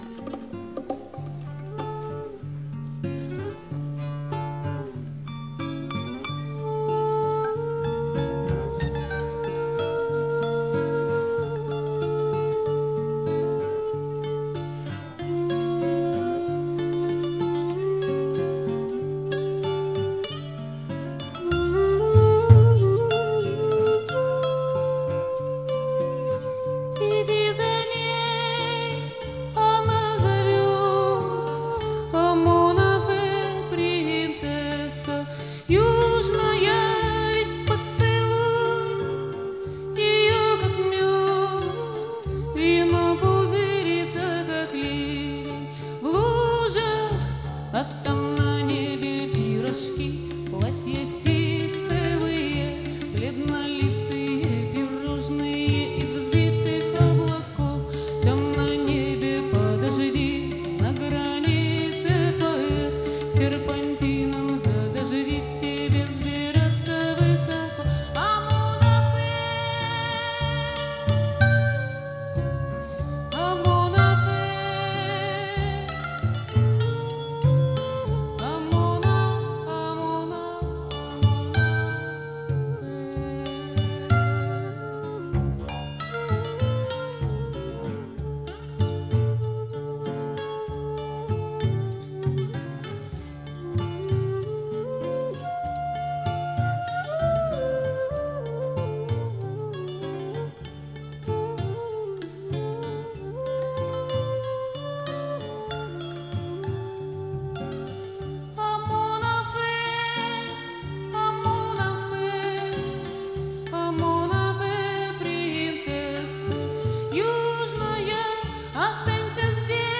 Концертный двойник, стиль — акустика.
голос, гитара
виолончель, голос
флейты, гитары, перкуссия, голос
мандолина, голос
перкуссия, голос